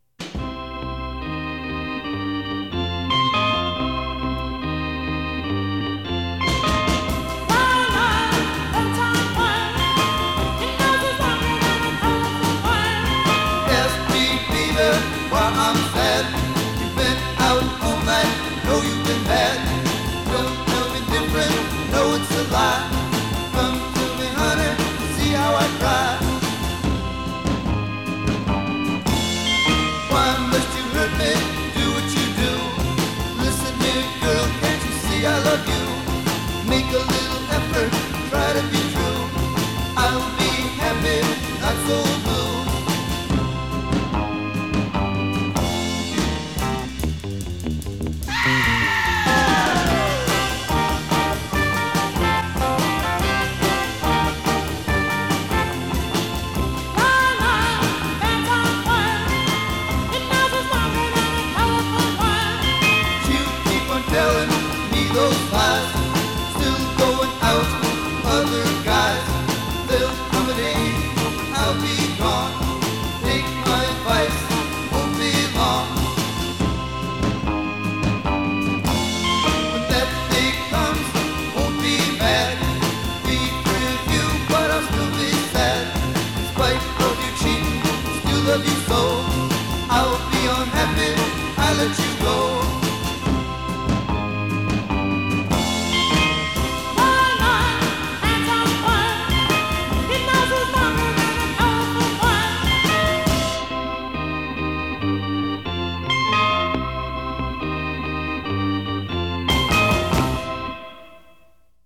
rock n roll